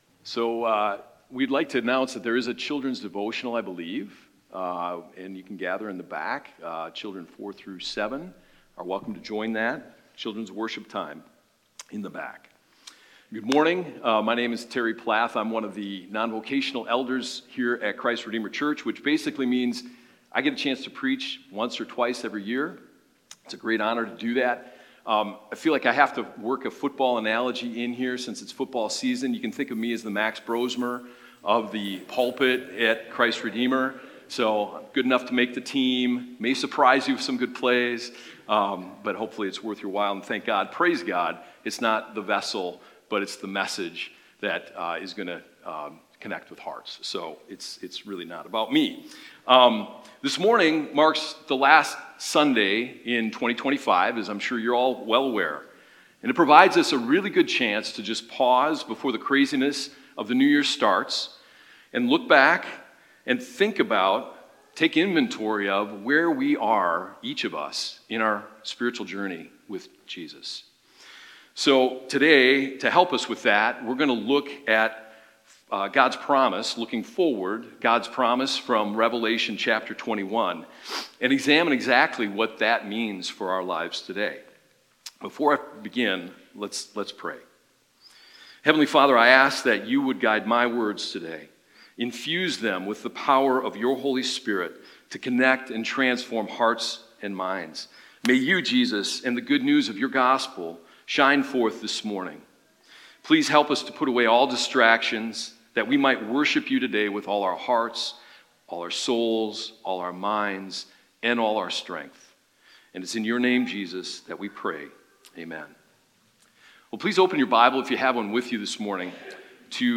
Weekly Sunday Sermons from Christ Redeemer Church in Cottage Grove, MN